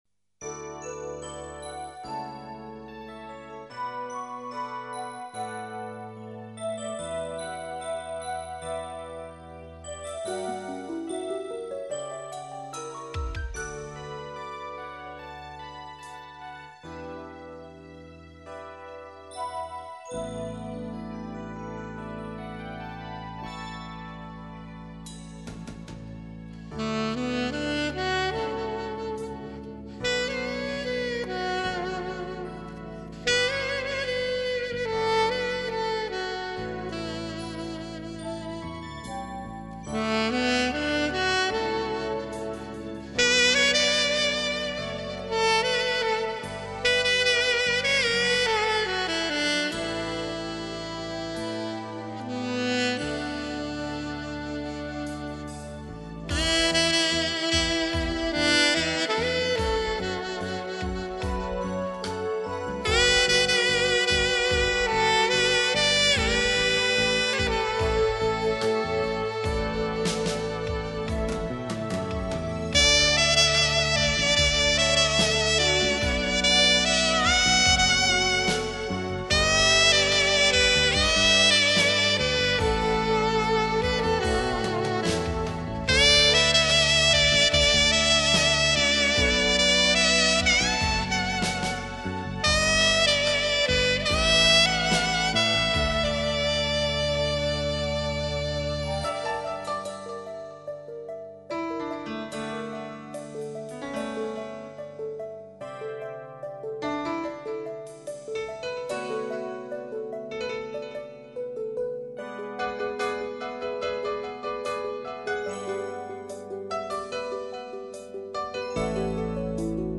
萨克斯演奏